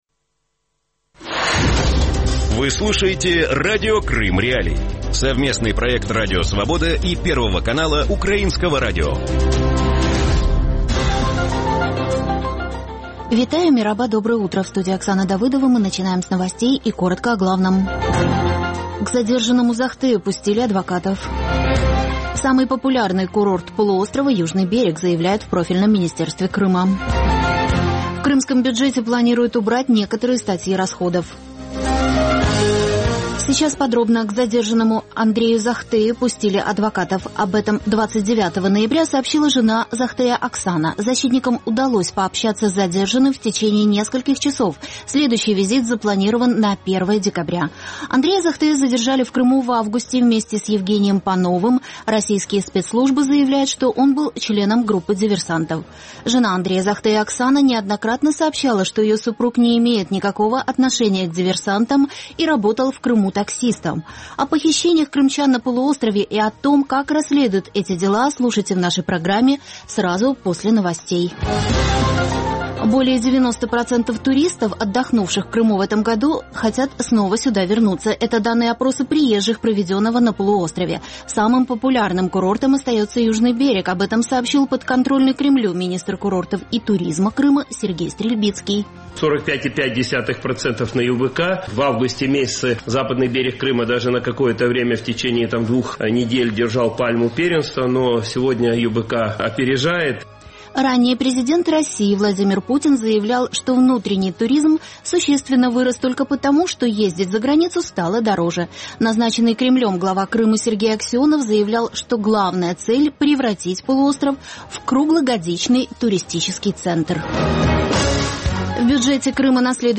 Утренний выпуск новостей о событиях в Крыму. Все самое важное, что случилось к этому часу на полуострове.